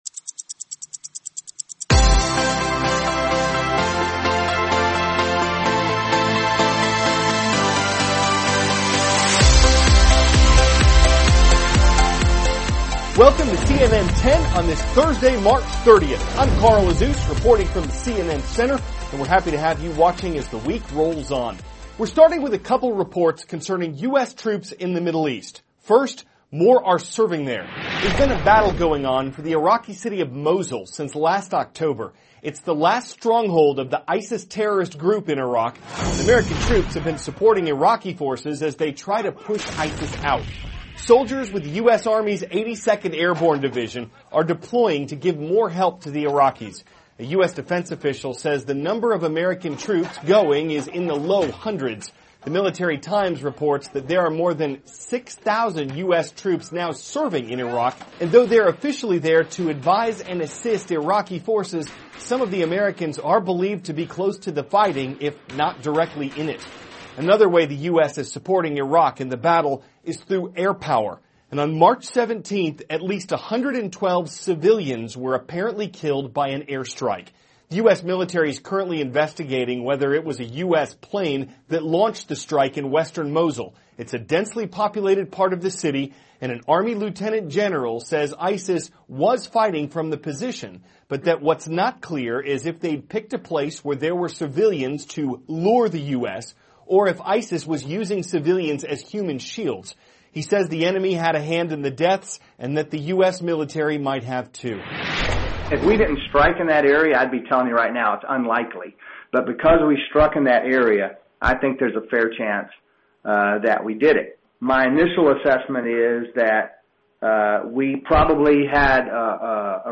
*** CARL AZUZ, cnn 10 ANCHOR: Welcome to cnn 10 on this Thursday, March 30th.